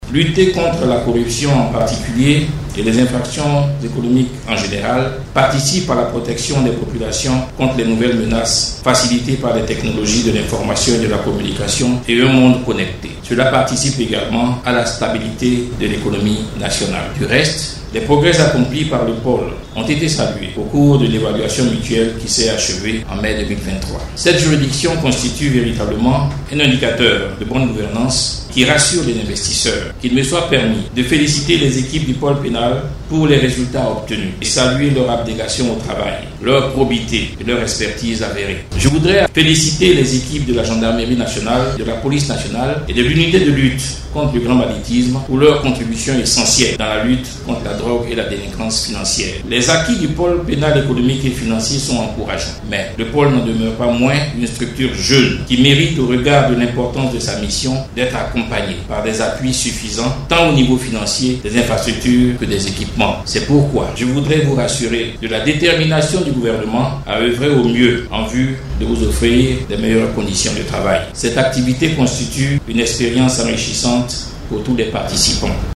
Jean Sansan Kambile, Garde des Sceaux, Ministre de la Justice - Journée Porte Ouverte du Pôle Pénal Economique et Financier